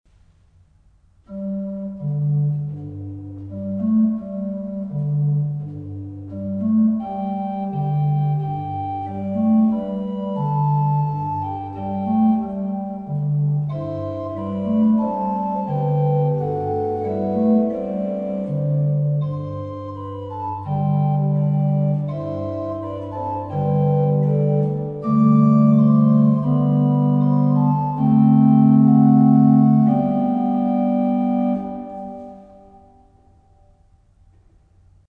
Orgelvorspiele
Hier finden Sie einige Orgelvorspiele zu Liedern aus dem Gotteslob.
gg_931_christen-_lasst_die_knie_uns_beugen_orgelvorspiel.mp3